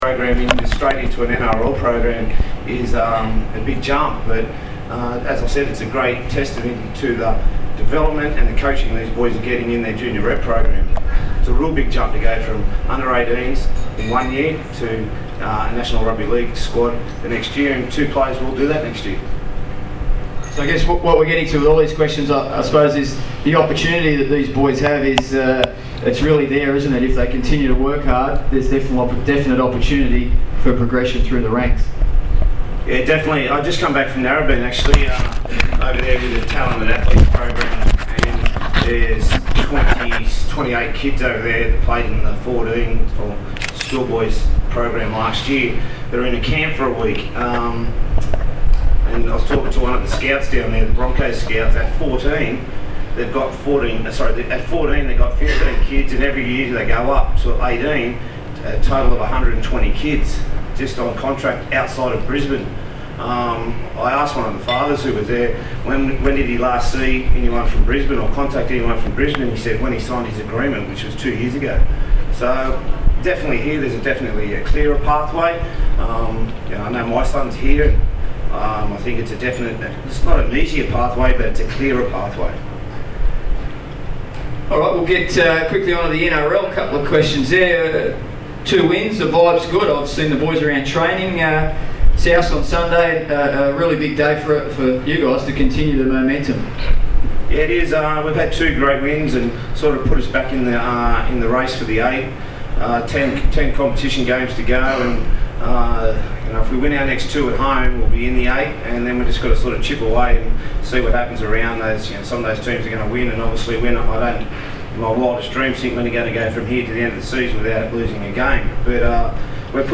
PARRAMATTA EELS SING VICTORY SONG